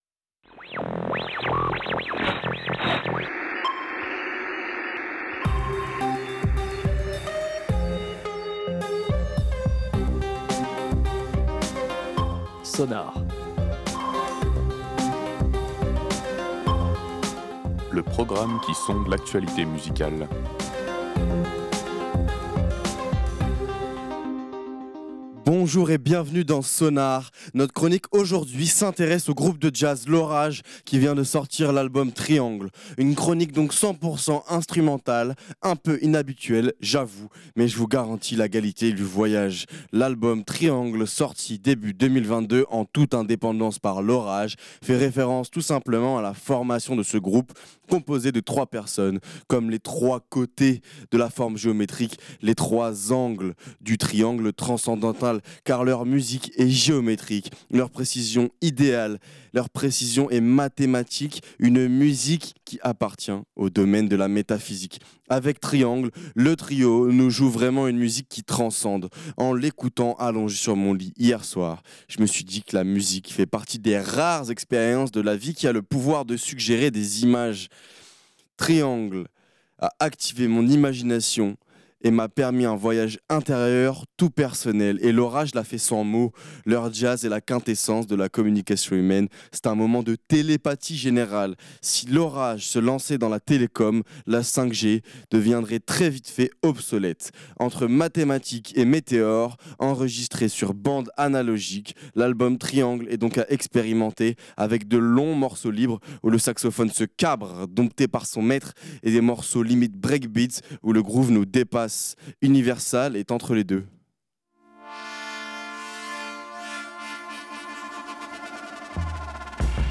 jazz
enregistré sur bandes analogiques